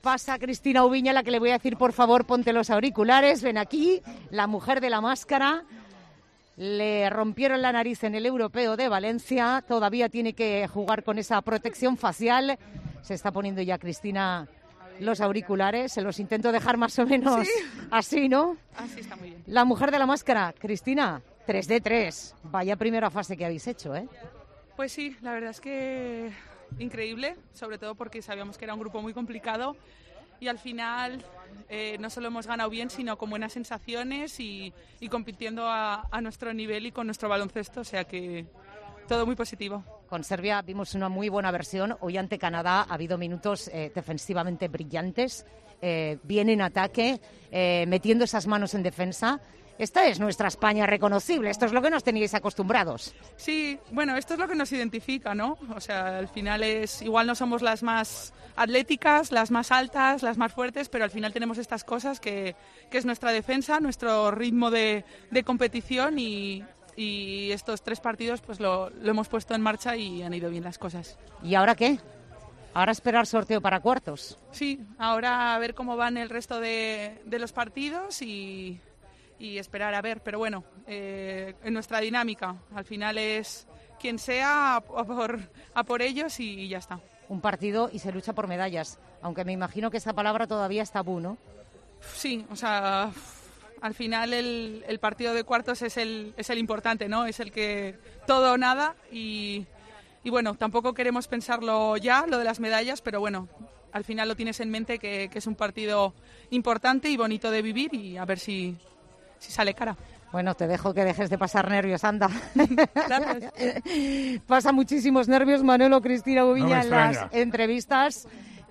La jugadora de la selección femenina de baloncesto ha atendido a Tiempo de Juego tras la victoria ante Canadá en el último partido de la fase de grupos.